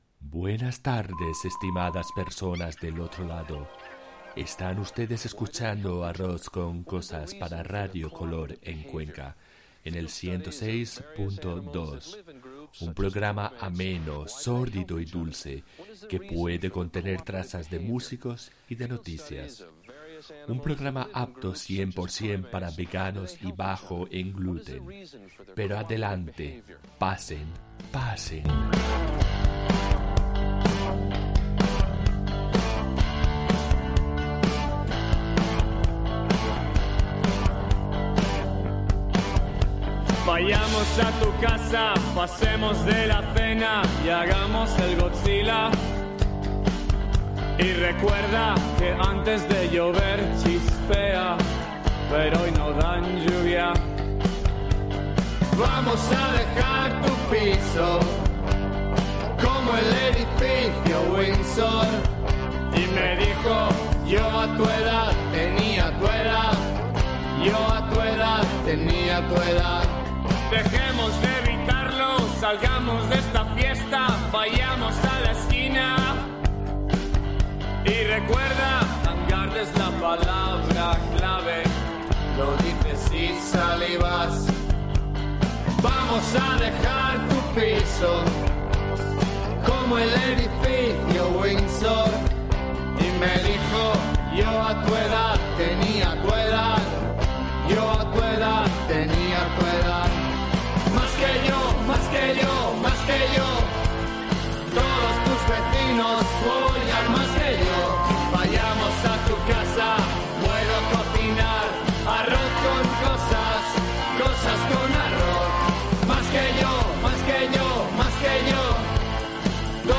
Arroz con Cosases un programa idiota para Radio Kolor, un espacio para la musica emergente, gente de la zona que lleva a cabo proyectos interesantes, sostenibles y pioneros.